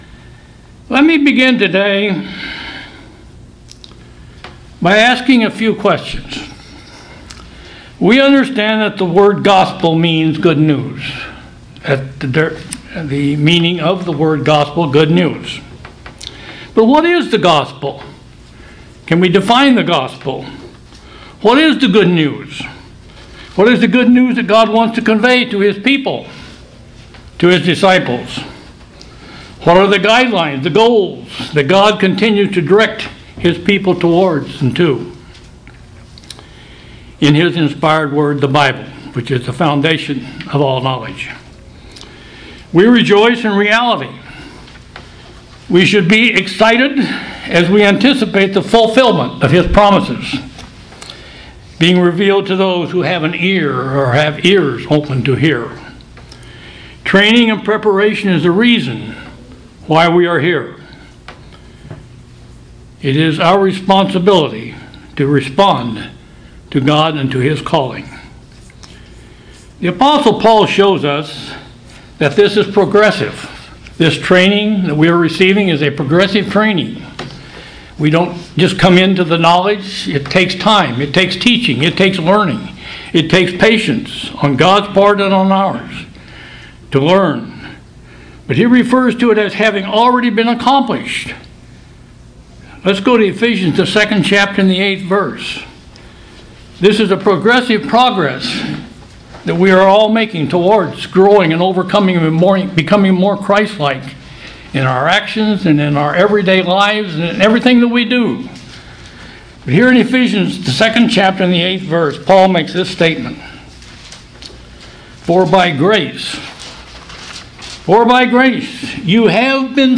Sermons
Given in Kansas City, KS